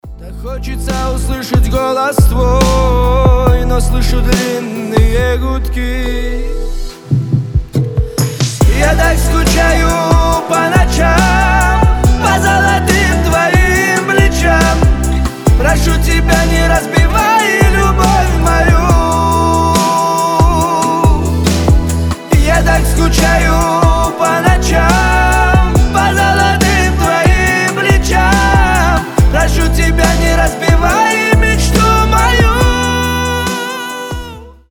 • Качество: 320, Stereo
грустные